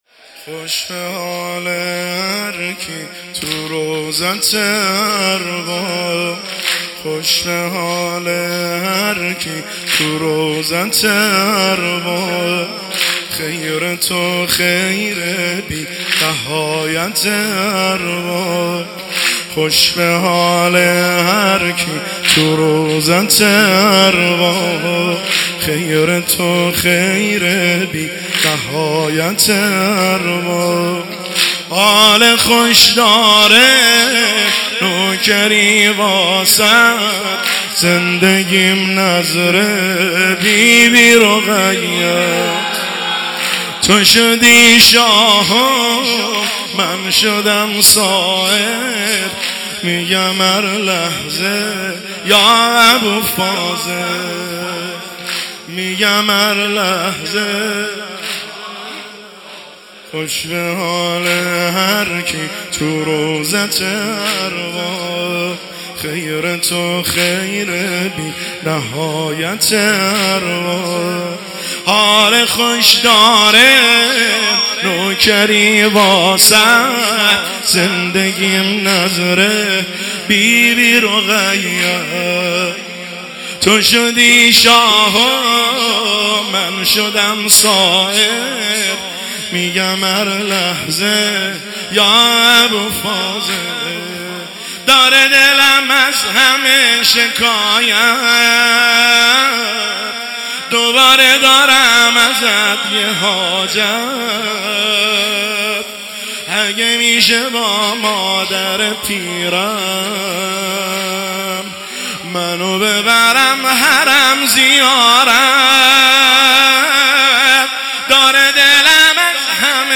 خوش بحال هر کی توو روضته ارباب واحد – شب دوم ایام مسلمیه 1404 هیئت بین الحرمین طهران